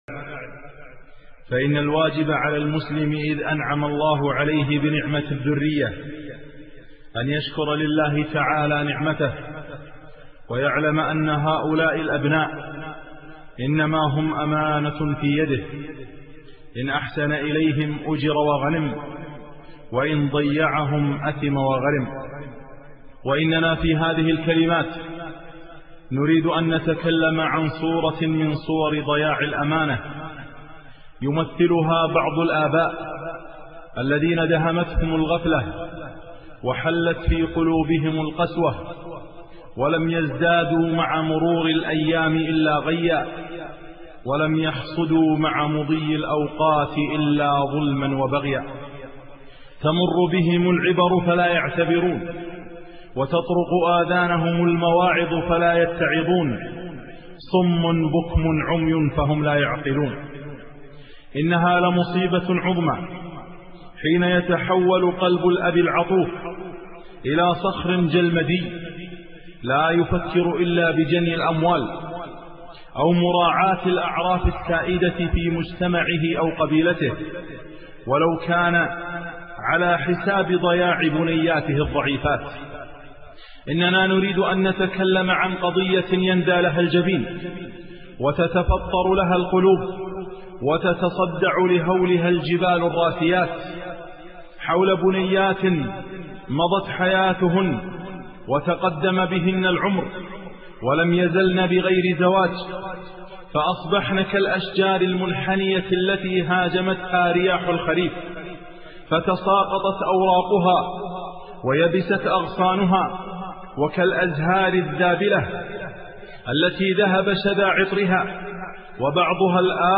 محاضرة - السجينة